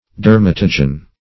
Dermatogen \Der*mat"o*gen\, n. [Gr. de`rma, -atos, skin + -gen.]